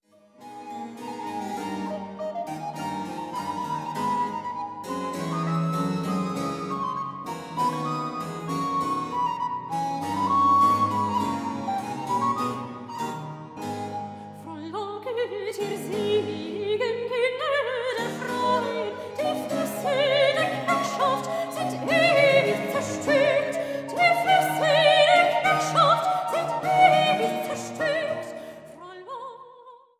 die amerikanische Sopranistin